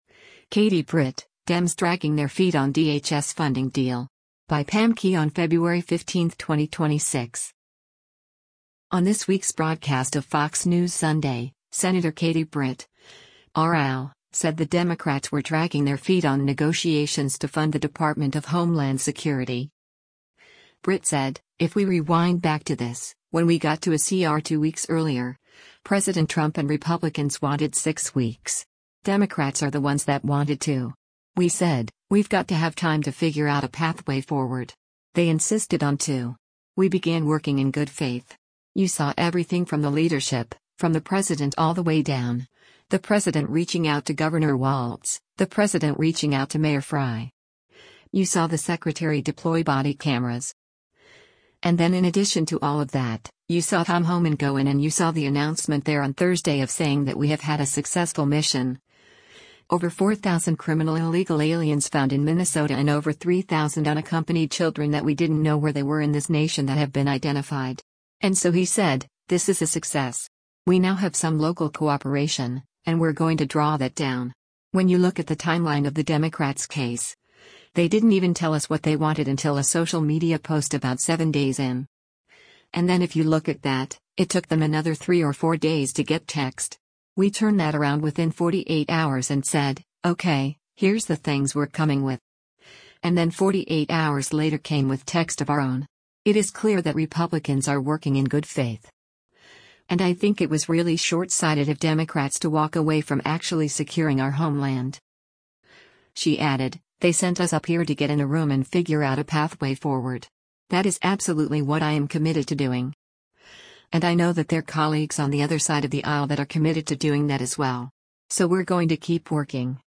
On this week’s broadcast of “Fox News Sunday,” Sen. Katie Britt (R-AL) said the Democrats were “dragging their feet” on negotiations to fund the Department of Homeland Security.